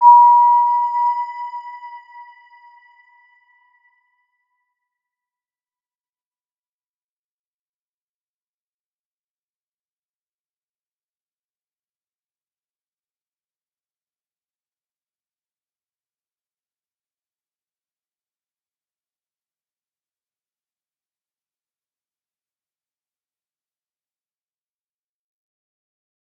Round-Bell-B5-mf.wav